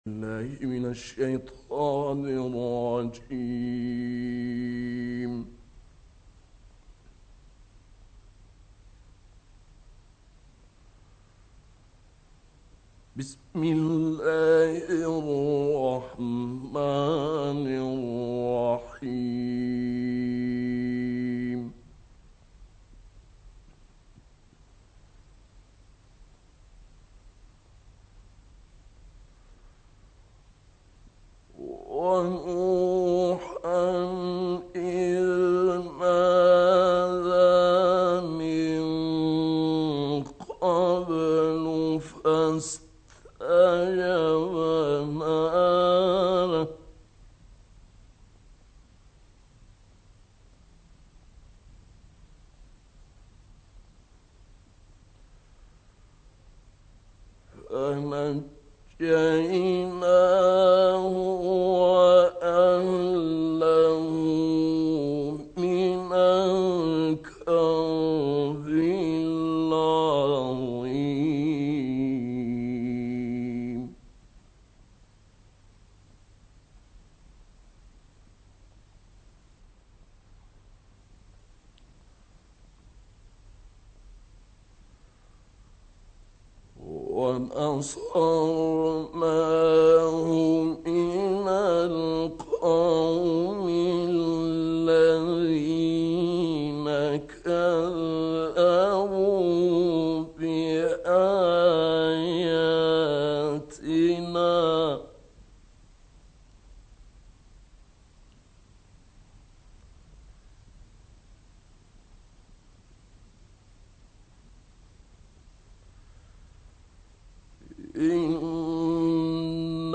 گروه چندرسانه‌ای ــ تلاوت آیات 76 تا 97 سوره مبارکه الأنبیاء با صوت ابوالعینین شعیشع، قاری به نام مصری ارائه می‌شود.